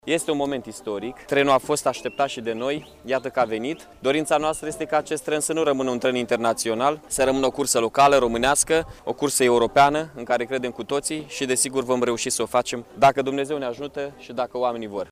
La rândul său, primarul interimar al Iaşului, Mihai Chirica, a declarat că evenimentul de astăzi precede momentul din 2018 când vor fi marcaţi 100 de ani de la Unirea Basarabiei cu România: